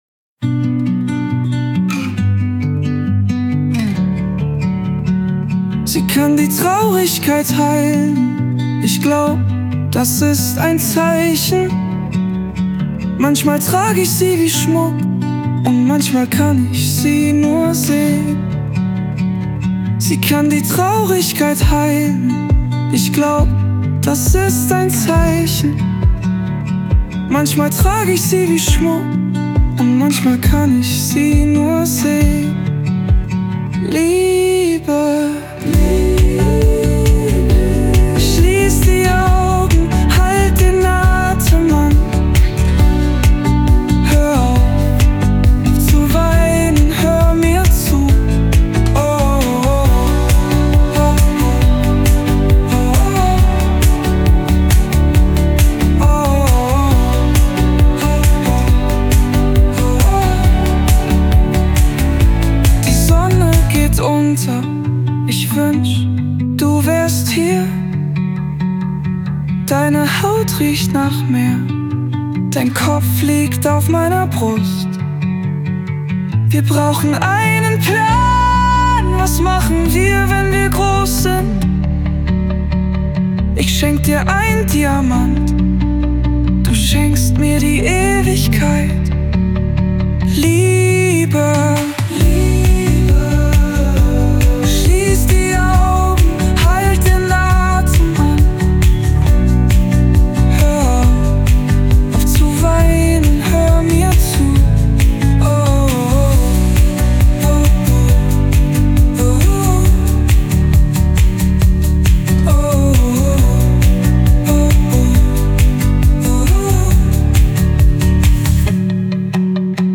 Beispiel für die Komposition eines deutschen Liebesliedes, auf Basis des zuvor generierten Songtextes (siehe oben)  – realisiert mit Suno: